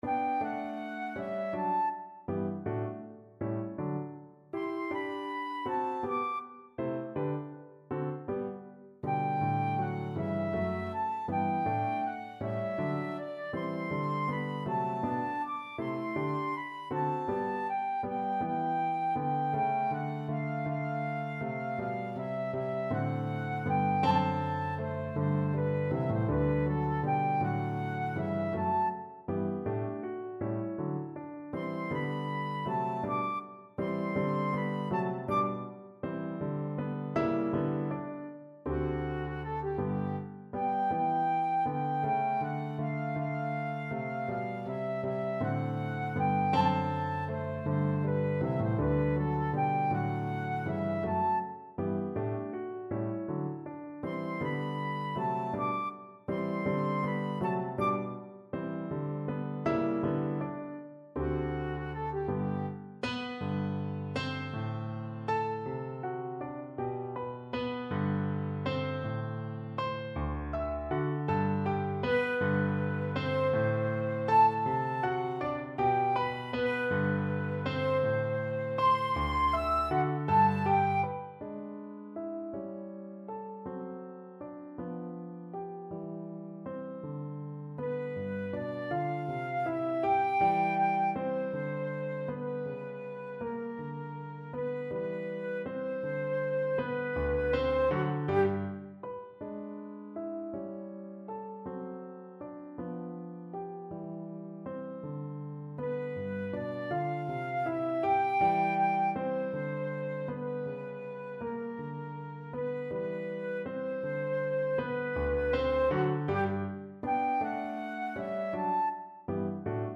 Classical Beethoven, Ludwig van Moonlight Sonata (2nd Movement) Flute version
3/4 (View more 3/4 Music)
G major (Sounding Pitch) (View more G major Music for Flute )
II: Allegretto =160
Classical (View more Classical Flute Music)